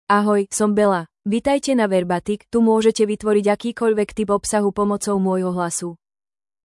BellaFemale Slovak AI voice
Bella is a female AI voice for Slovak (Slovakia).
Voice sample
Listen to Bella's female Slovak voice.
Bella delivers clear pronunciation with authentic Slovakia Slovak intonation, making your content sound professionally produced.